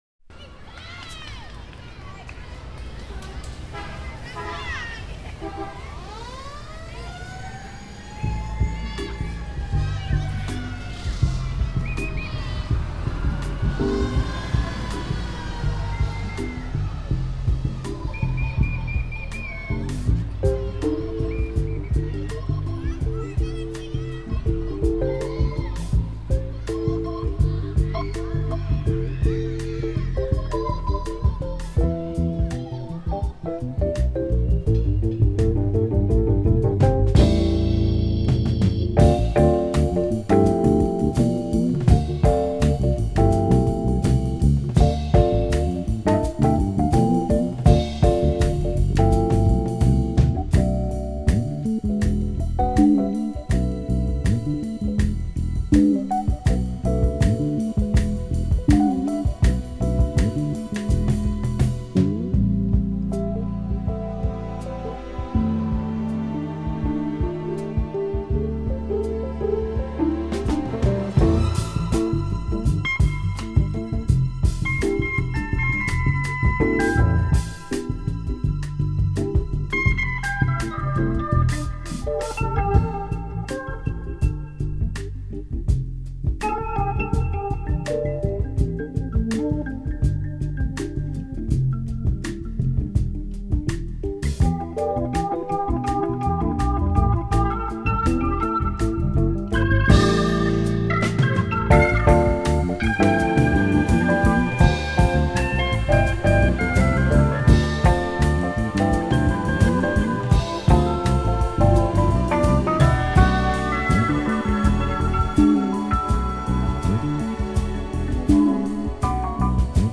Джаз